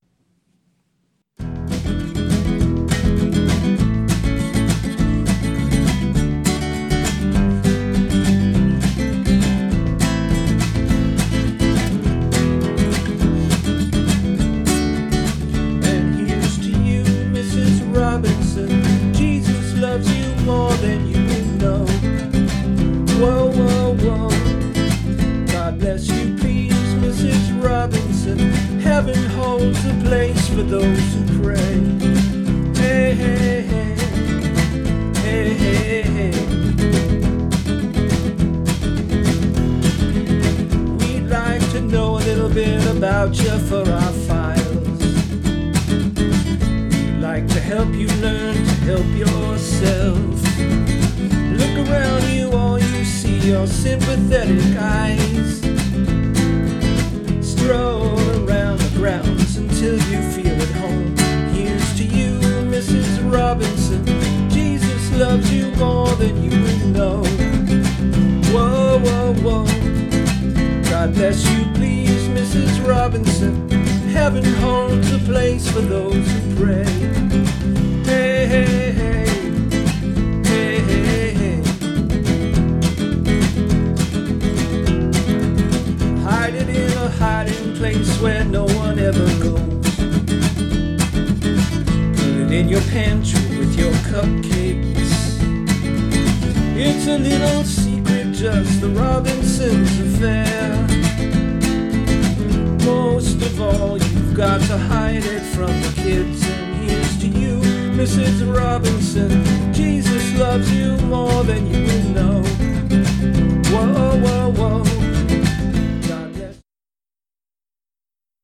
Genre: Rock.